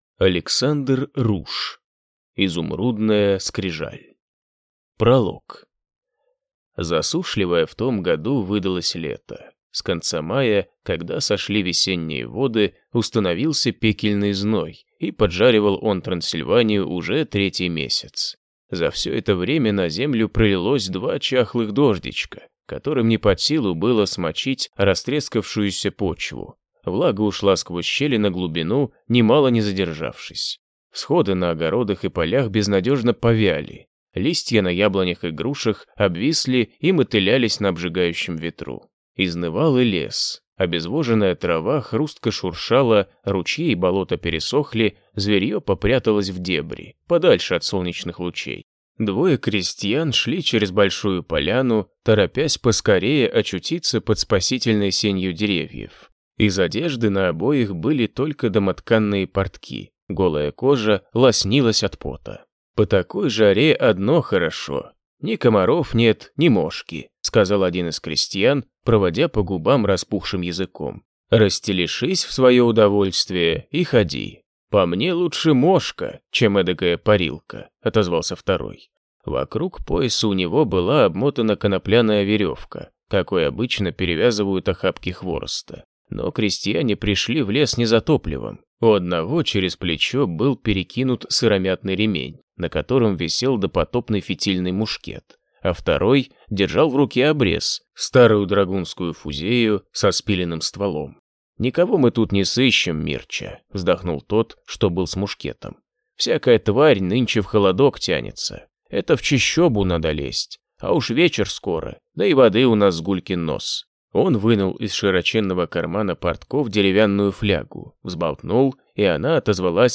Аудиокнига Изумрудная скрижаль | Библиотека аудиокниг